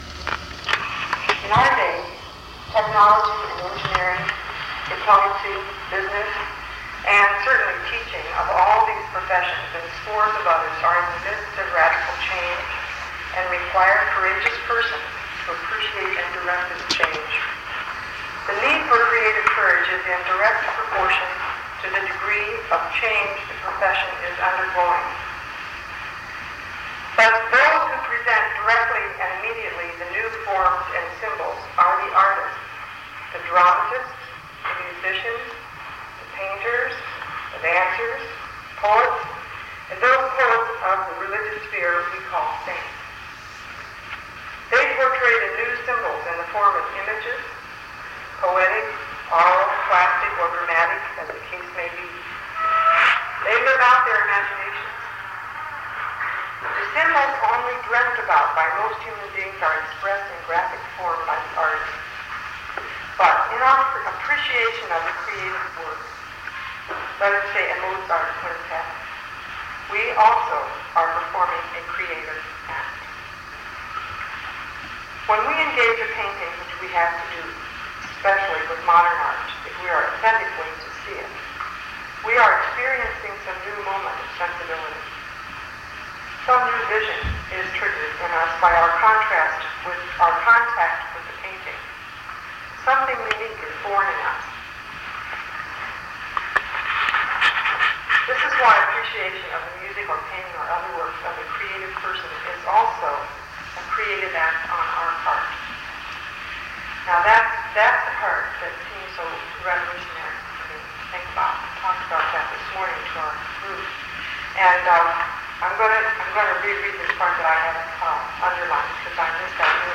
Location Internet Lecture at Grand Marais Art Colony, July 31, 1988. 1 master audio file (53 minutes, 44 seconds): WAV (271 MB) and 1 user audio file: MP3 (34.3 MB).